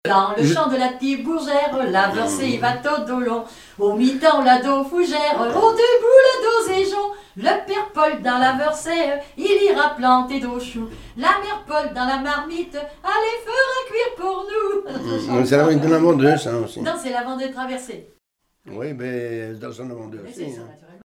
danse : branle : avant-deux
Répertoire de marches de noces et d'avant-deux
Pièce musicale inédite